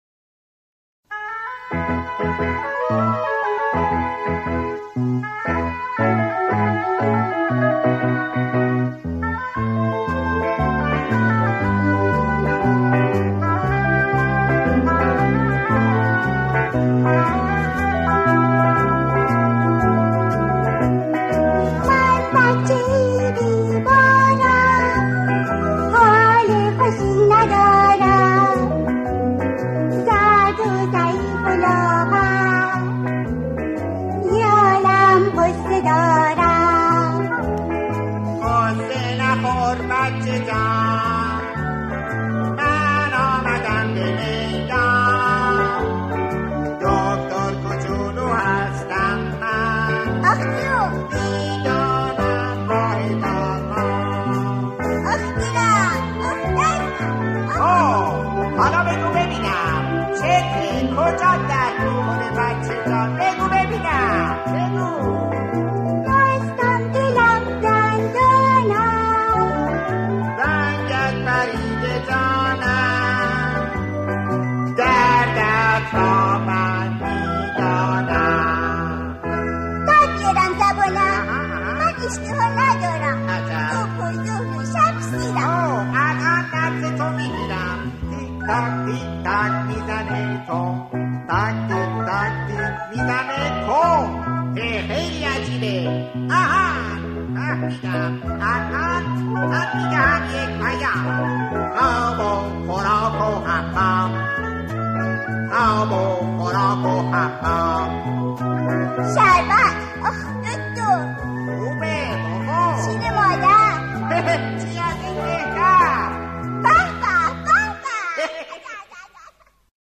سرود کودکانه